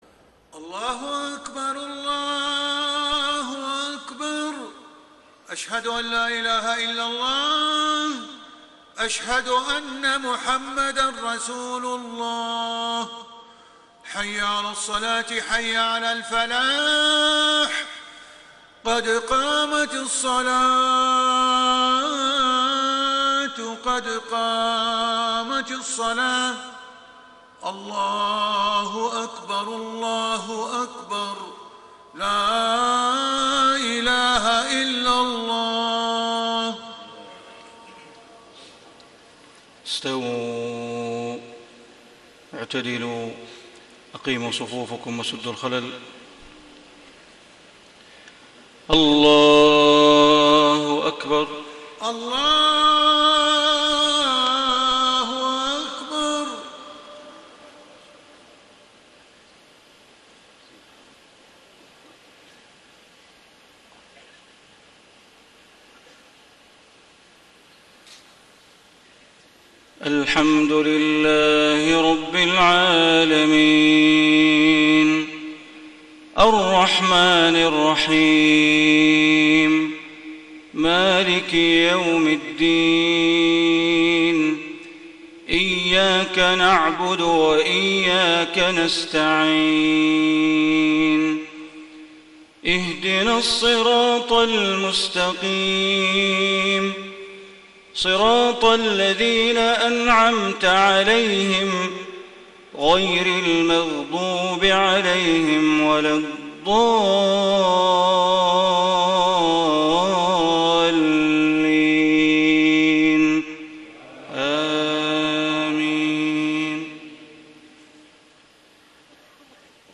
صلاة العشاء 2 - 1 - 1435هـ من سورتي يونس و الزمر > 1435 🕋 > الفروض - تلاوات الحرمين